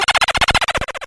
BooLaugh.wav